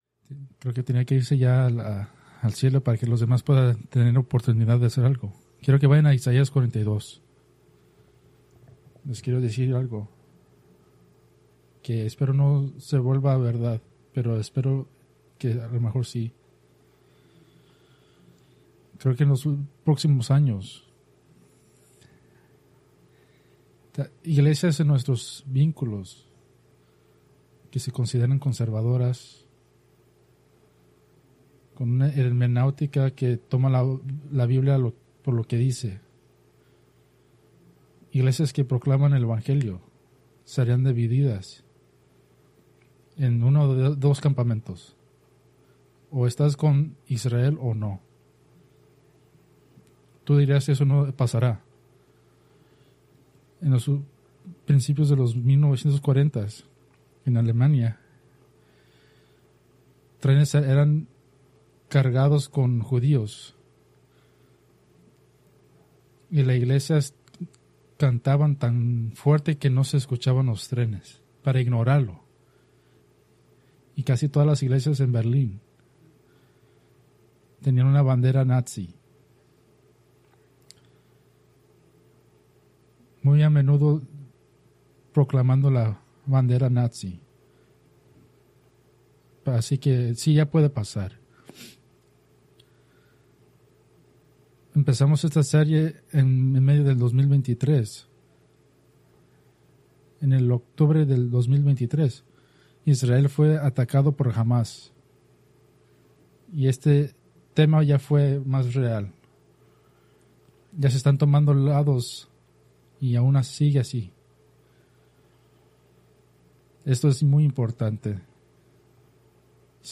Preached November 9, 2025 from Escrituras seleccionadas